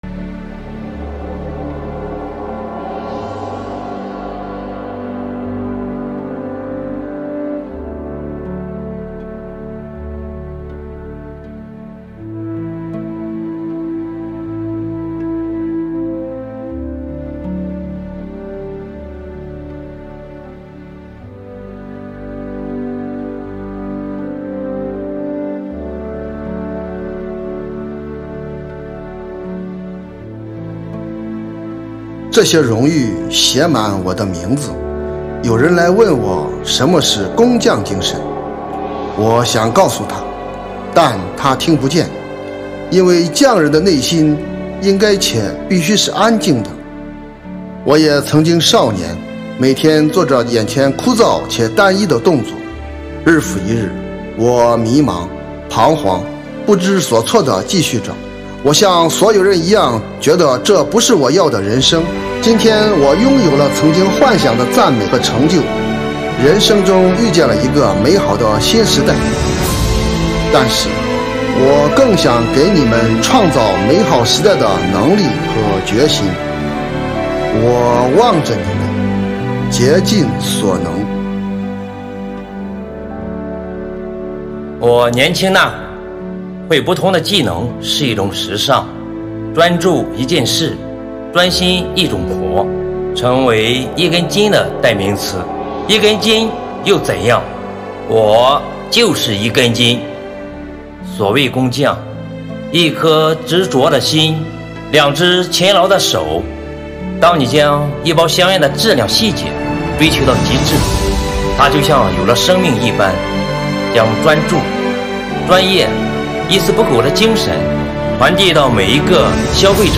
- 主题诵读作品 -